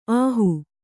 ♪ āhu